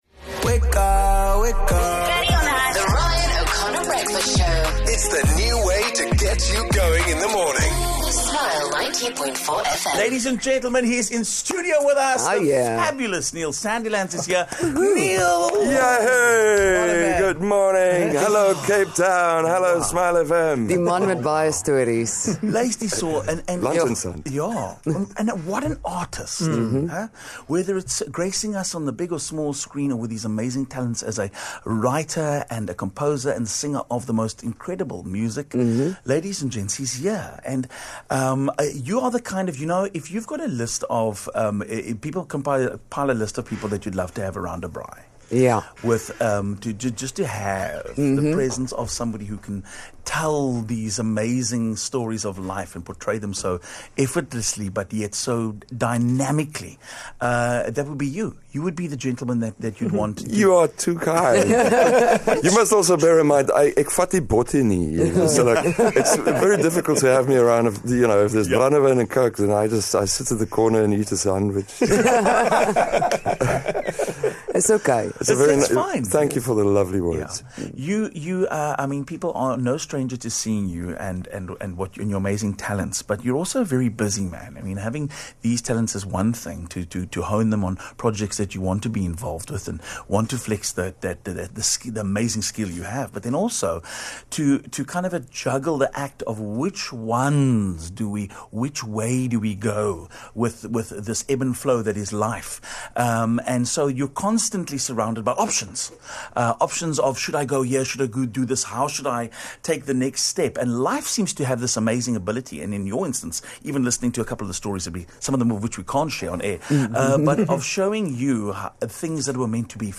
Neil Sandilands is back in South Africa with some exciting projects on the horizon. He popped into our studios for a chat.